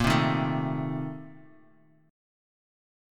A#M7sus2sus4 chord